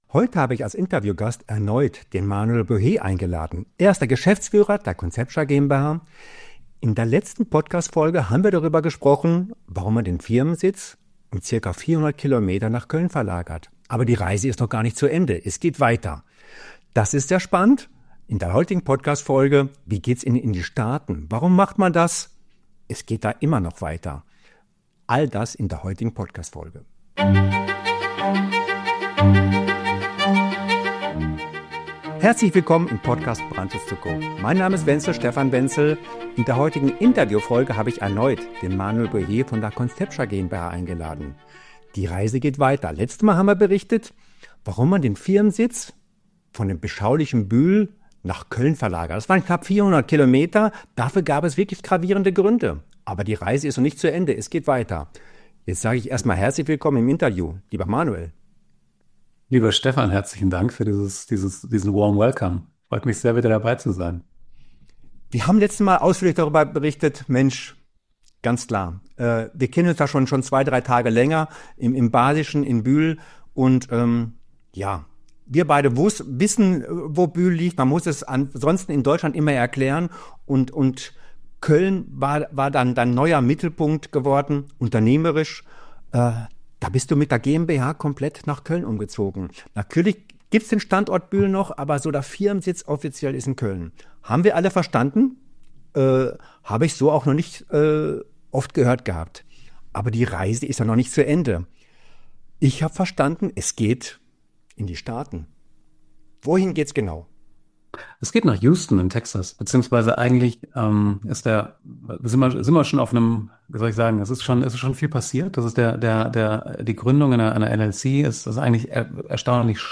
Interview-Folge